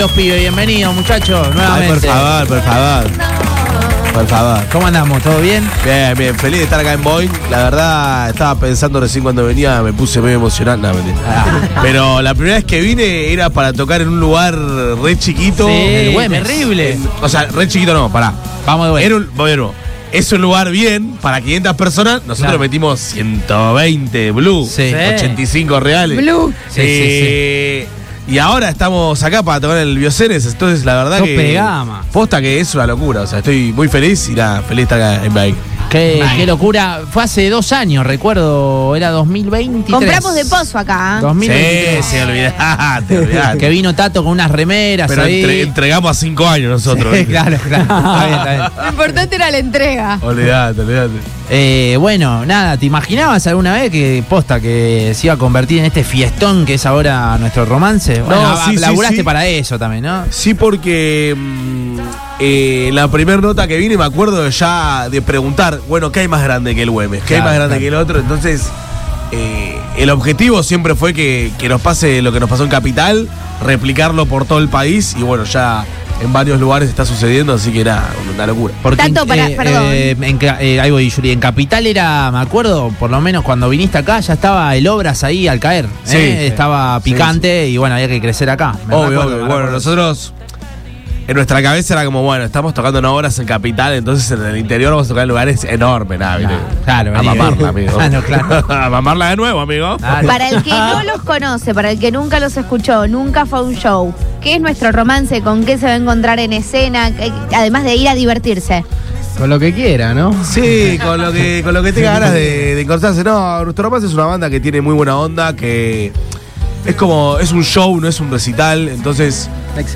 La banda pasó por Boing y sus Secuaces y contó sobre su show en Rosario.